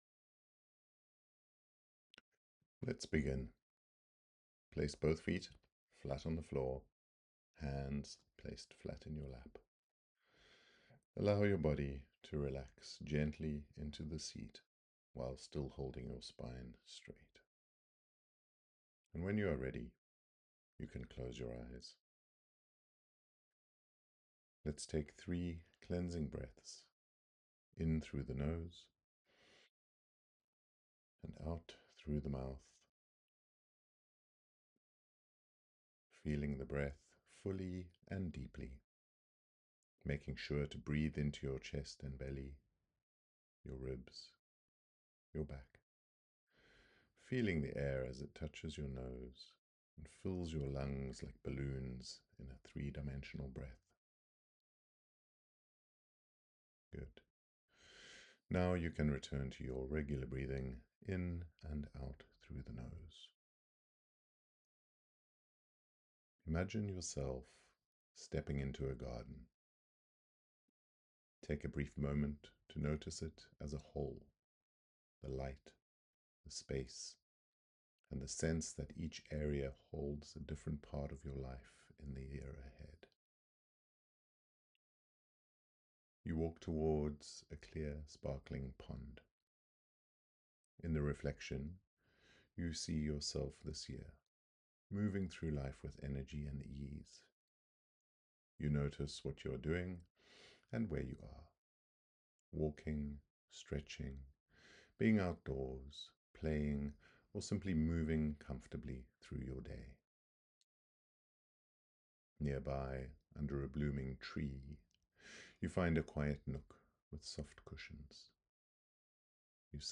Manifeast Meditation